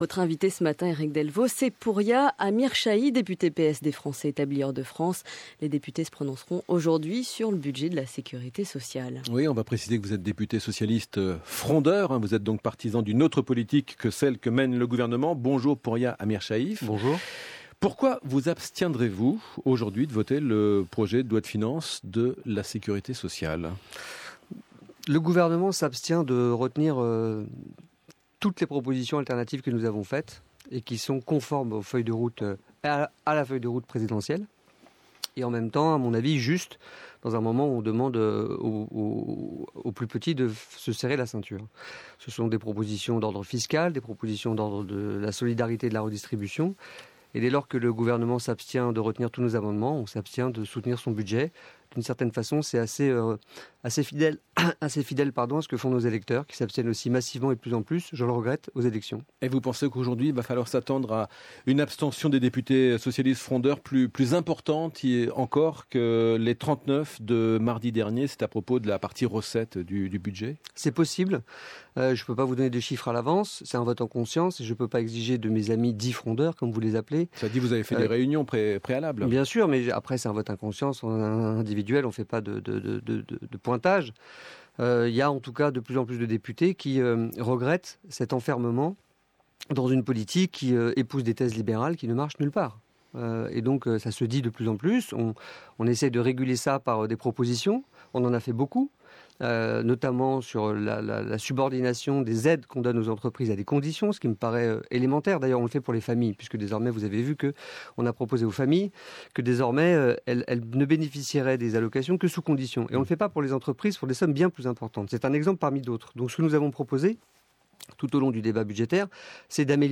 J'étais ce matin l'invité du 5/7 sur France inter :